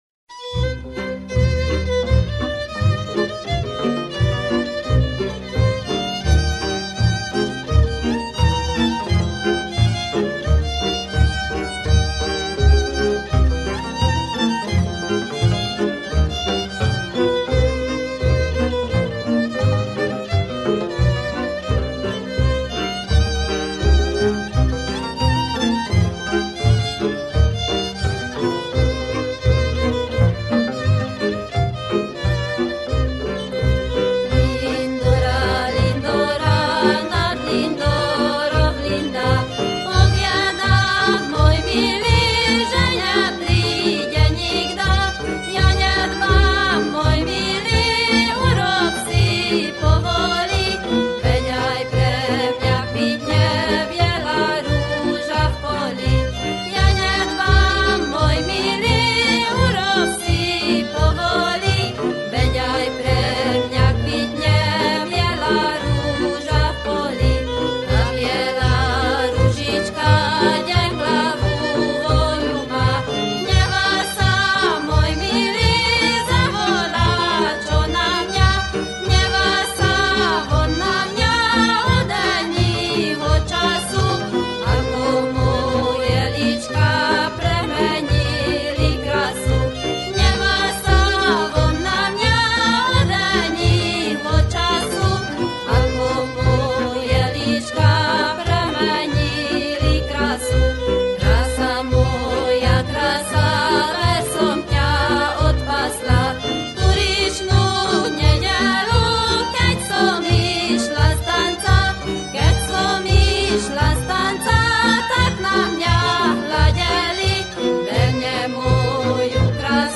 Válogatás mezőberényi népdalokból